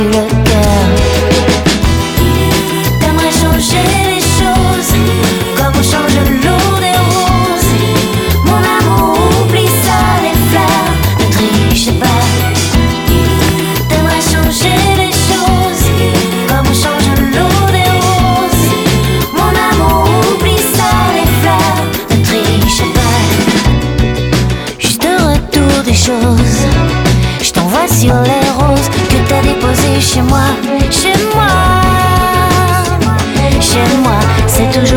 French Pop